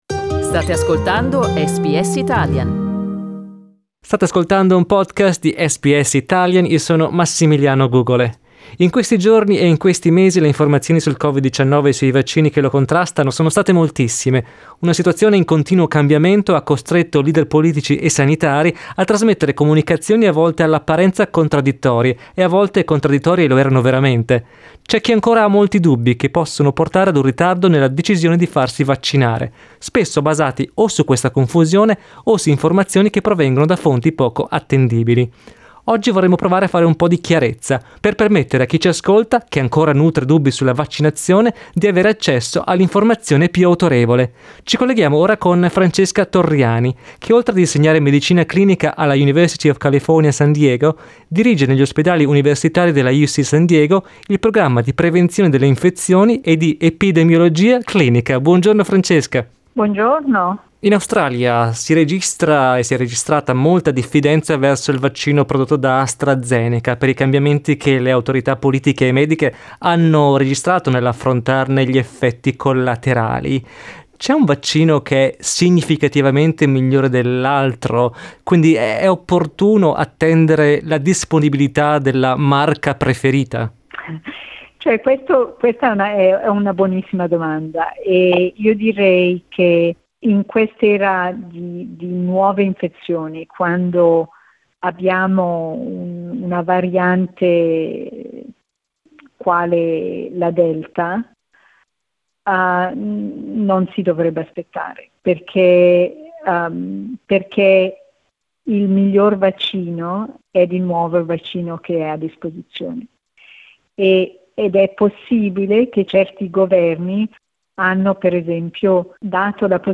Ascolta la prima parte dell'intervista